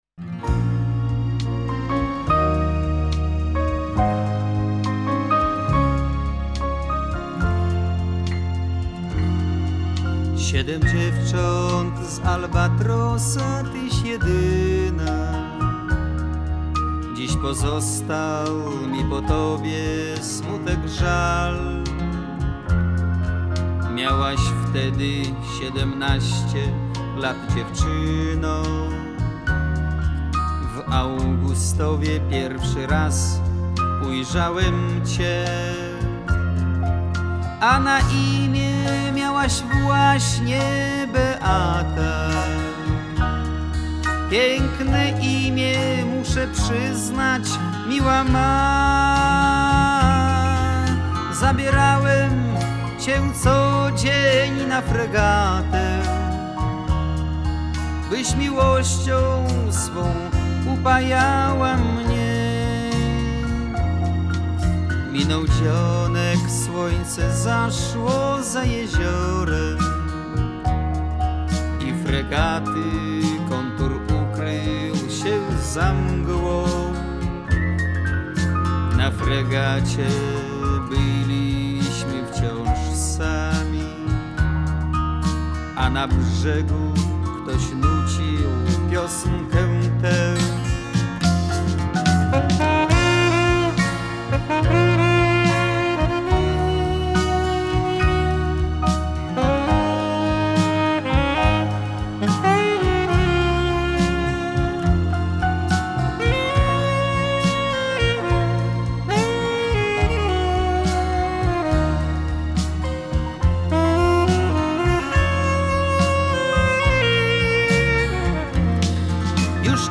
(pianino/orkiestra, 1977)